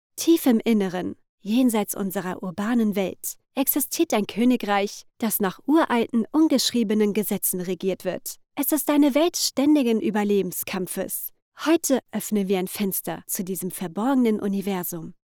Teenager (13-17) | Yng Adult (18-29)